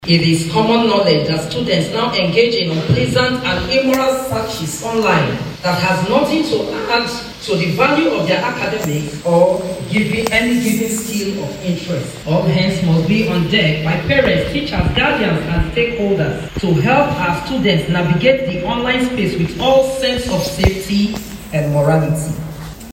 This was made known during a Digital Safety Conference for Secondary Schools organised by SieDi Hub, a non-governmental organisation, in collaboration with National Orientation Agency (NOA) and other development partners in Umuahia.